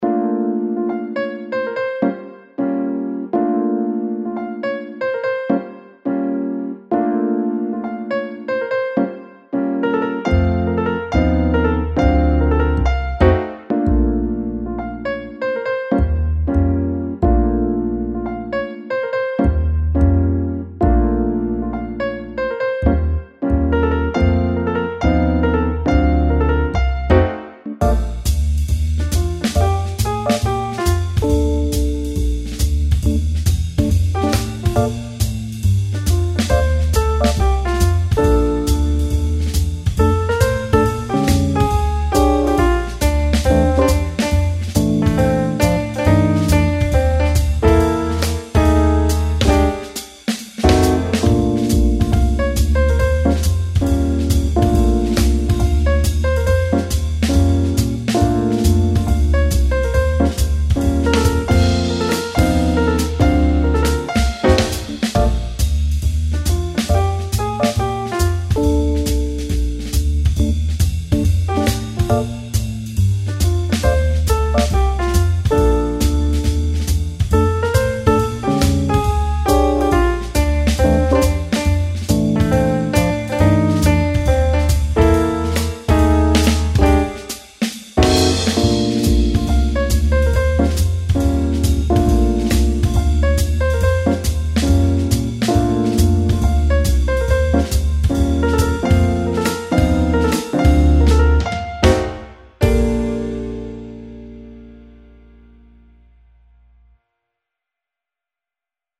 Allegro [130-140] plaisir - piano - jazz - club - guitare
jazz - club - guitare